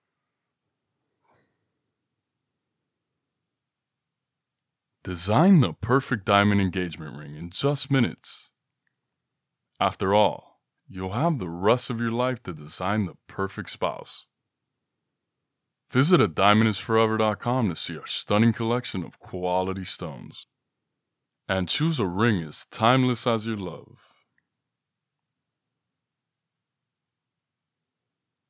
Diamond is Forever Online Ad
Young Adult
I have a naturally strong, clear voice with a broad range. My voice is primarily warm and smooth, yet authoritative with an intellectual subtleness.